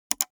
click.a152cbc8.mp3